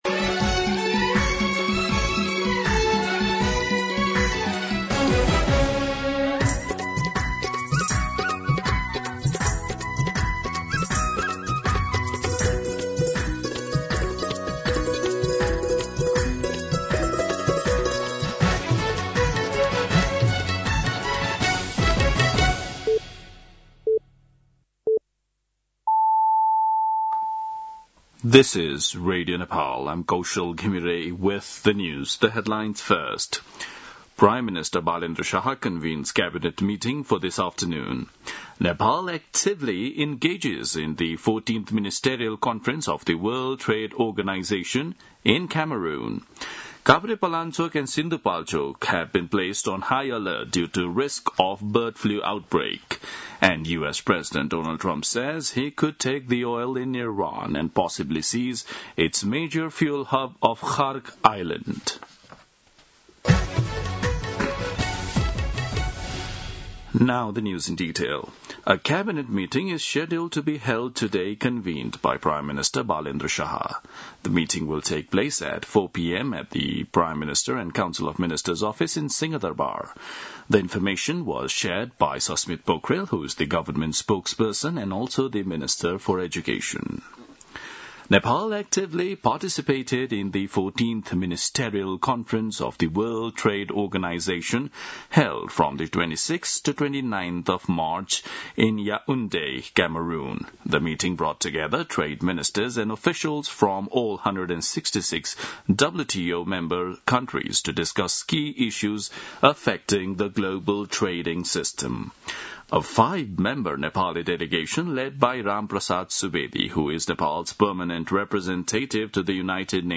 दिउँसो २ बजेको अङ्ग्रेजी समाचार : १६ चैत , २०८२
2-pm-English-News-12-16.mp3